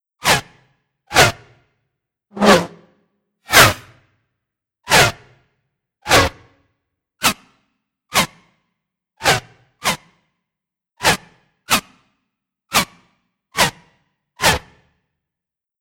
whizz.wav